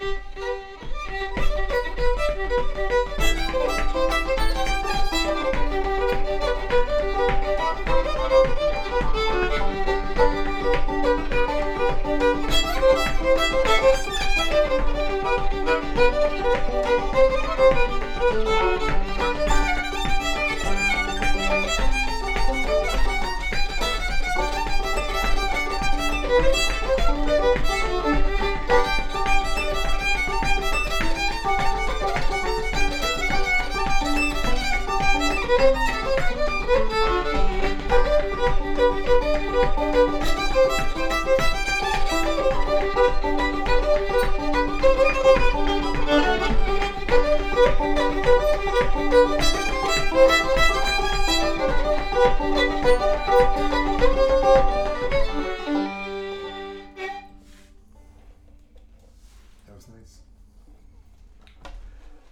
Princess Reel